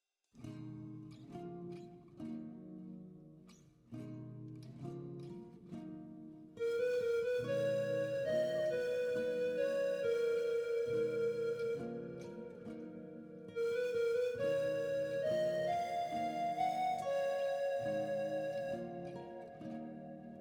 WIP on a panflute: